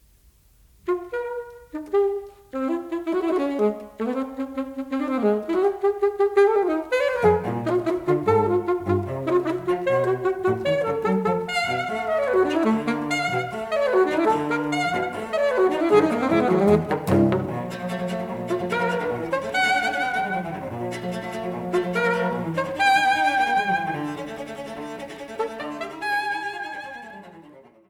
Rondo